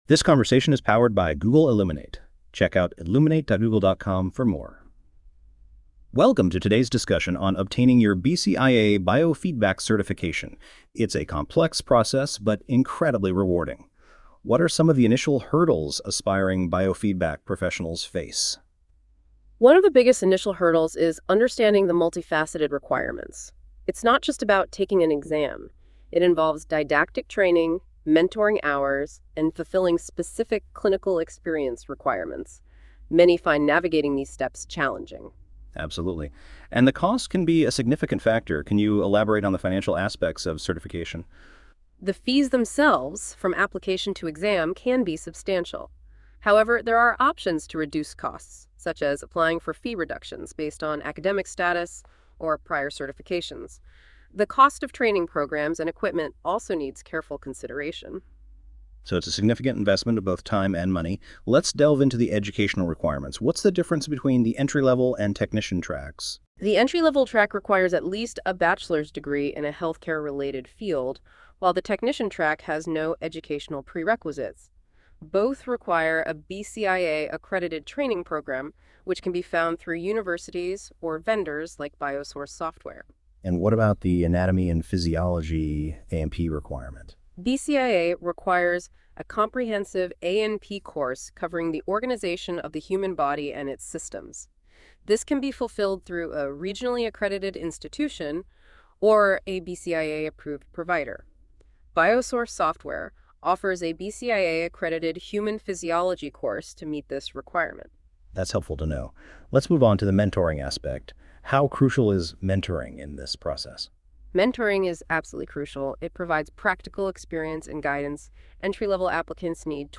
Click on the podcast icon for a Google Illuminate discussion.